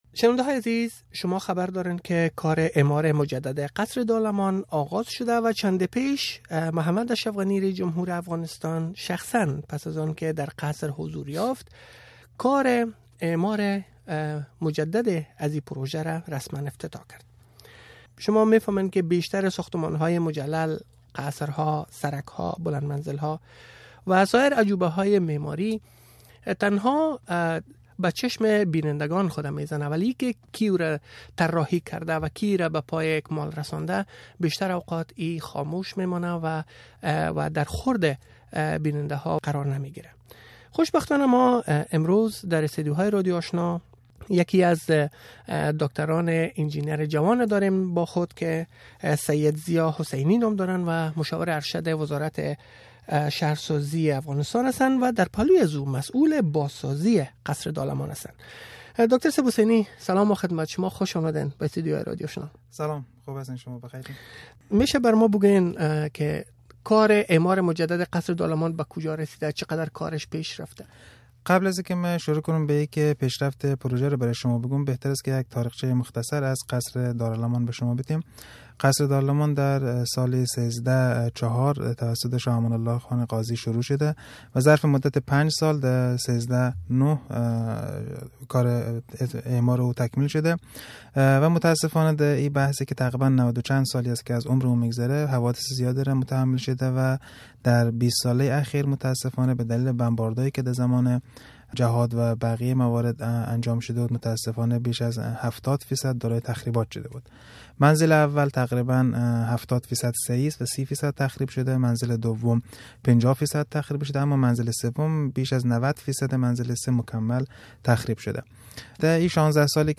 جریان کامل مصاحبه